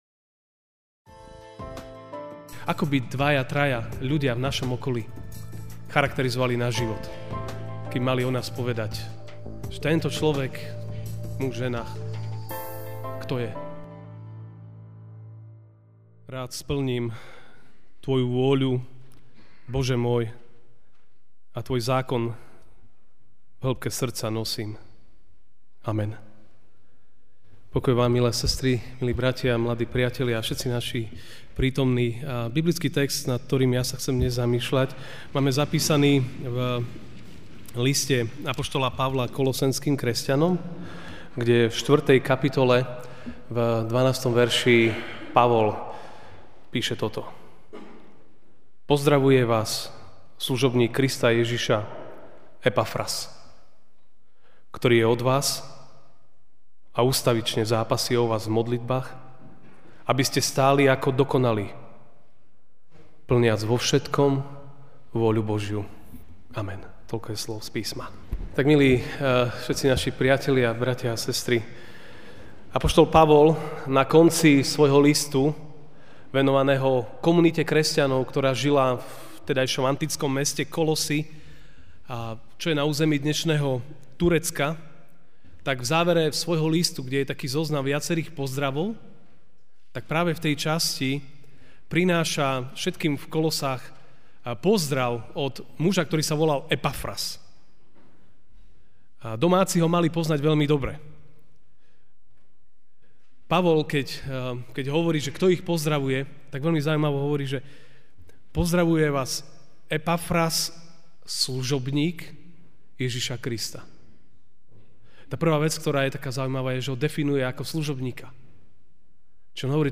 Ranná kázeň: O čo, ti v živote ide?